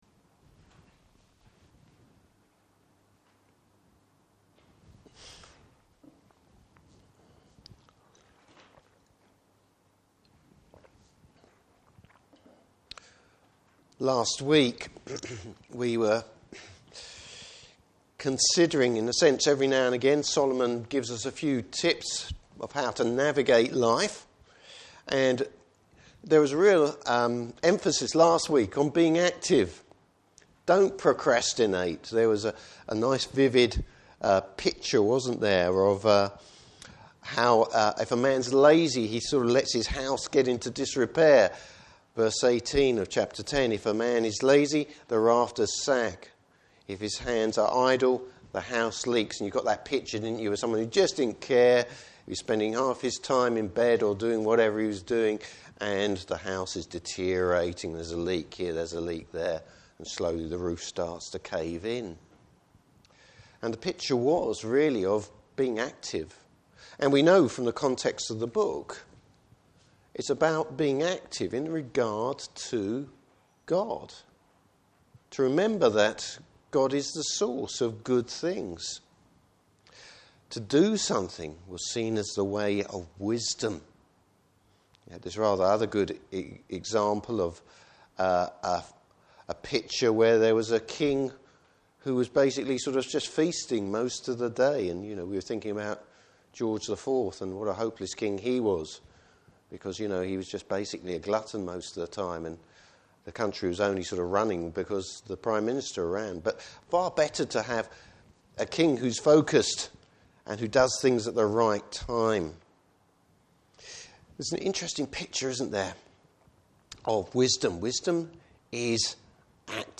Service Type: Morning Service Bible Text: Ecclesiastes 11:7-12:14.